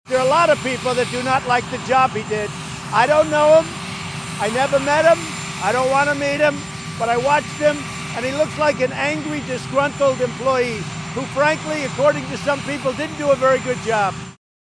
At the White House, President Donald Trump said Bright looked like an “angry, disgruntled employee.